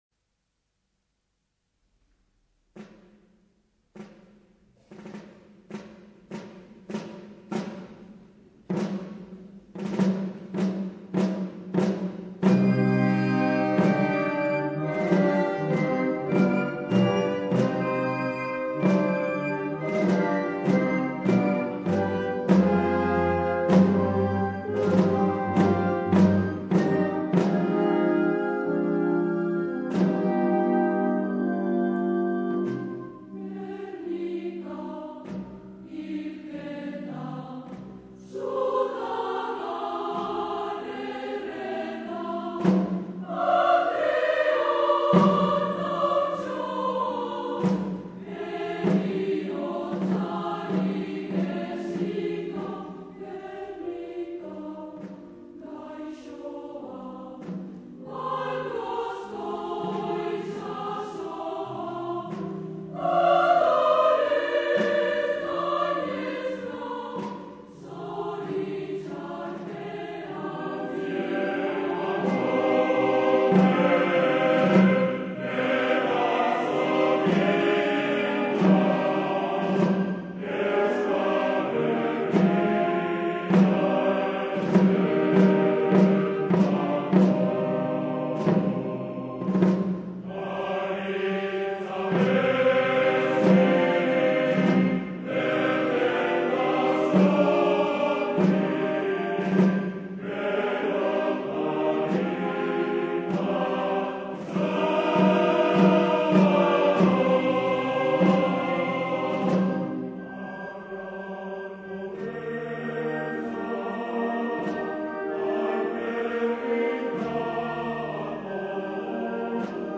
Día coral.
piano
órgano